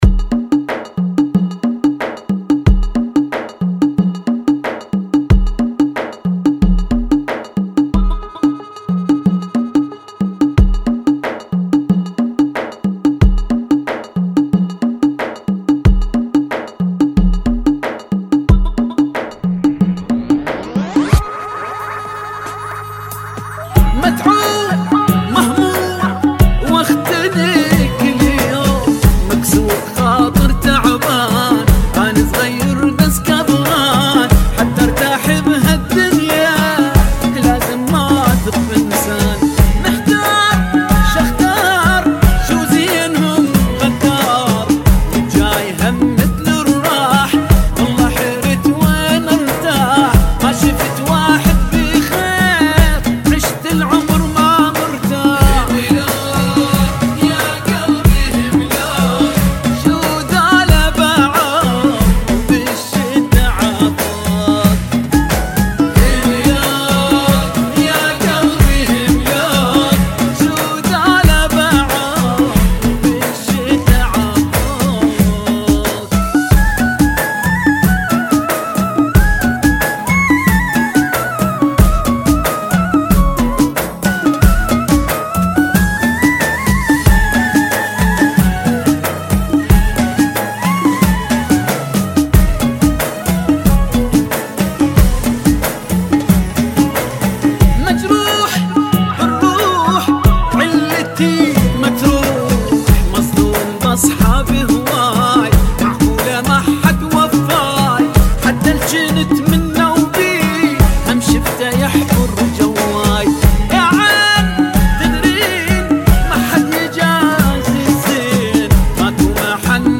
91 Bpm